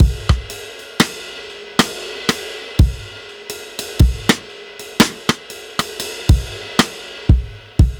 Unison Jazz - 4 - 120bpm.wav